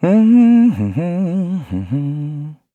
Kibera-Vox_Hum_kr.wav